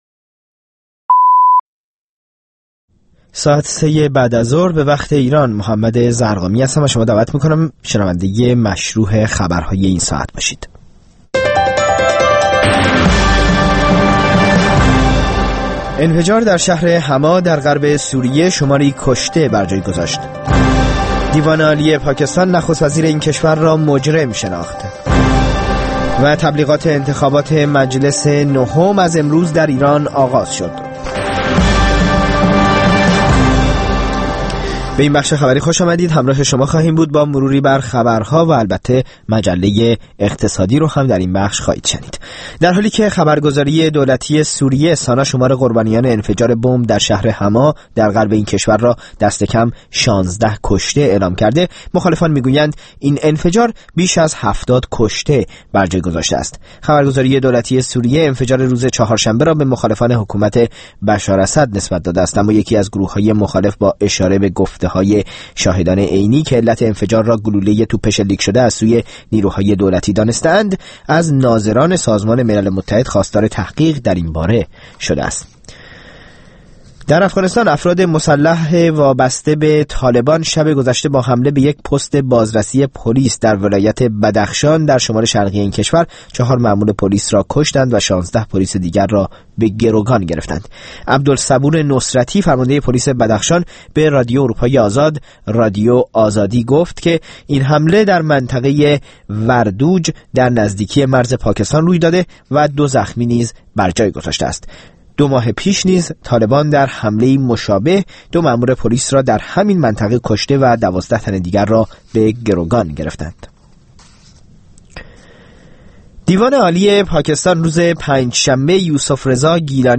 در مجله اقتصادی رویدادهای مهم اقتصادی ایران و جهان در طول هفته با کارشناسان در میان گذاشته و بررسی می‌شود.